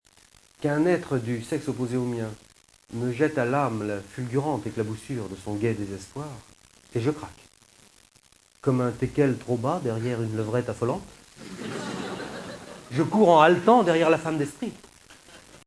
Théâtre Fontaine